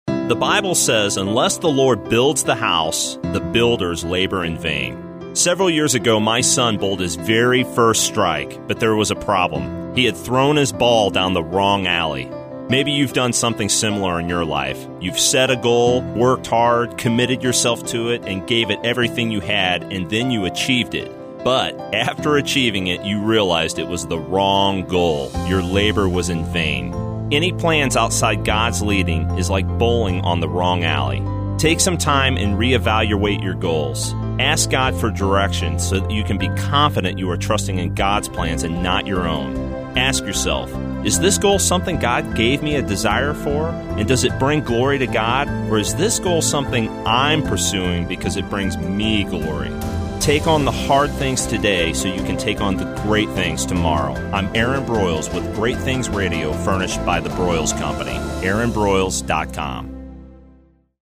I’m excited to introduce Great Things Radio (1 min motivational messages) airing now on Bott Radio Network on 91.5 FM in St. Louis at approximately 5:35 p.m. CST (top of the second break in the Bible Answer Man Hank Hanegraaff broadcast).